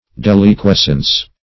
Search Result for " deliquescence" : The Collaborative International Dictionary of English v.0.48: Deliquescence \Del`i*ques"cence\, n. [Cf. F. d['e]liquescence.] The act of deliquescing or liquefying; process by which anything deliquesces; tendency to melt.